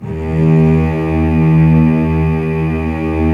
Index of /90_sSampleCDs/Roland LCDP13 String Sections/STR_Combos 2/CMB_MIDI Section
STR LUSH S01.wav